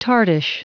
Prononciation du mot tartish en anglais (fichier audio)
Prononciation du mot : tartish